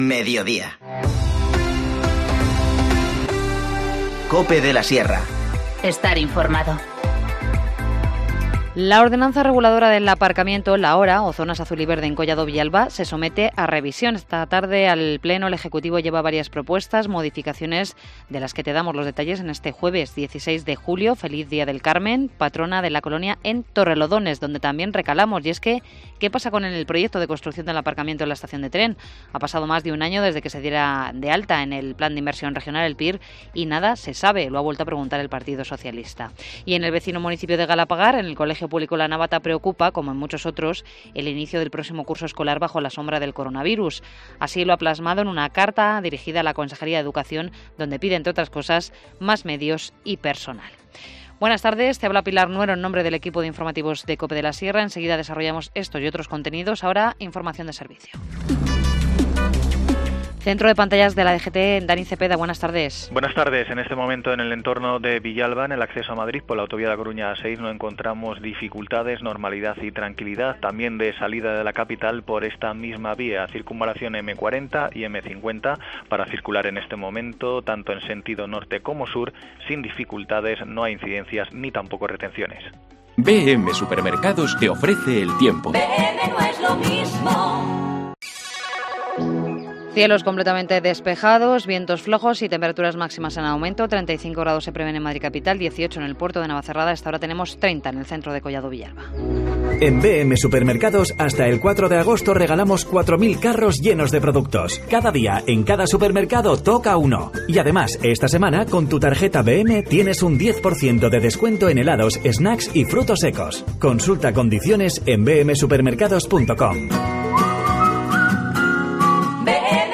Informativo Mediodía 16 julio
INFORMACIÓN LOCAL